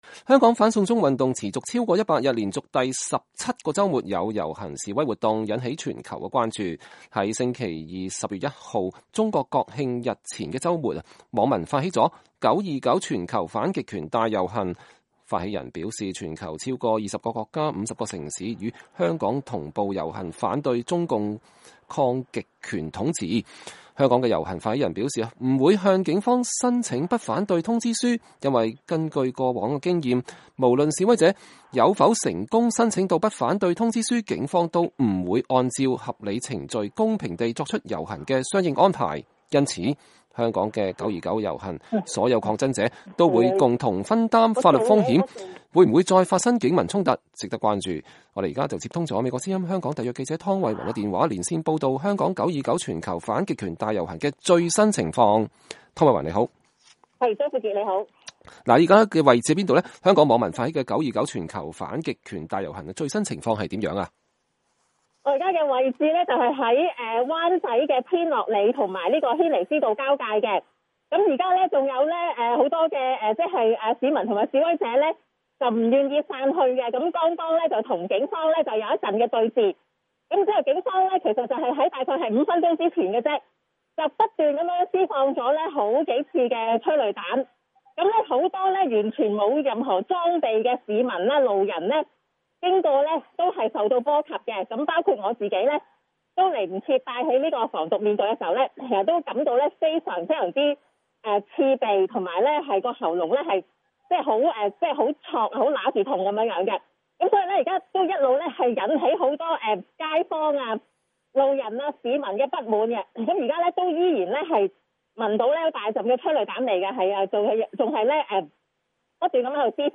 香港9-29全球反極權大遊行現場報導